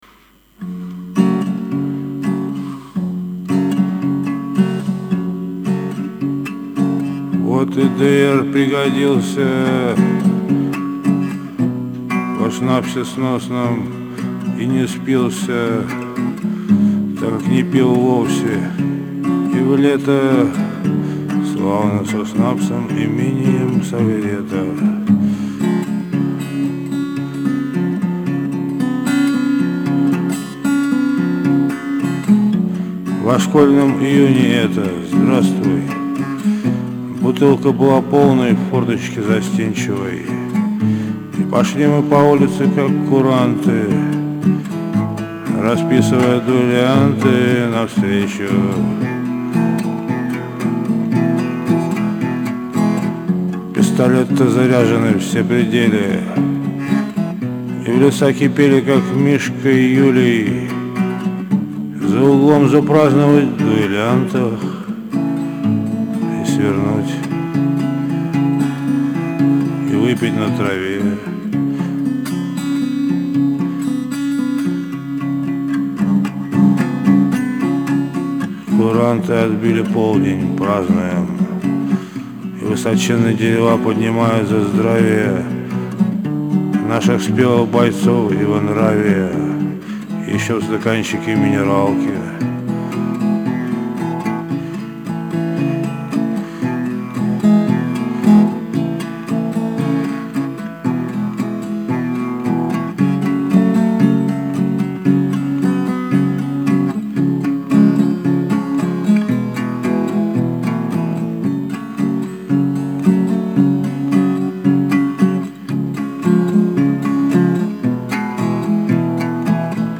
пестня "В лесу с Юлей"